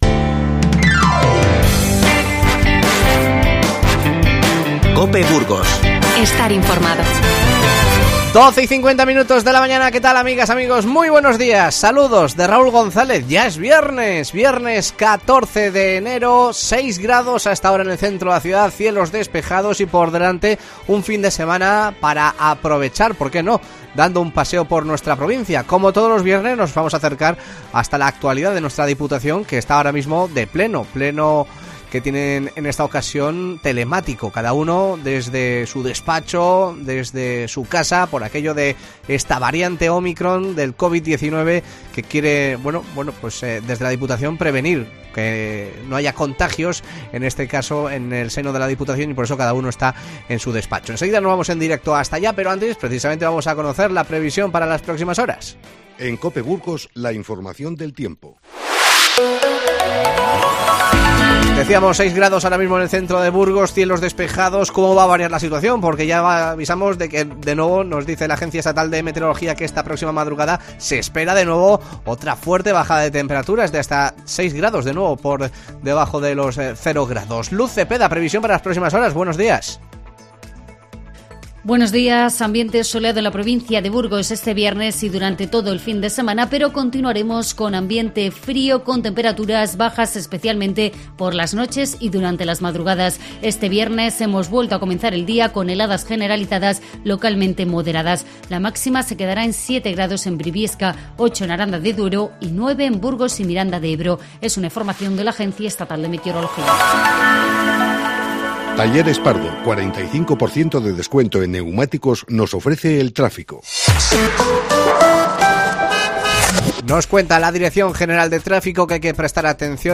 Te contamos que arranca la XVI Feria de Coleccionismo en el Fórum Evolución y nos vamos en directo hasta el pleno de la Diputación de Burgos para conocer qué proposiciones se están debatiendo.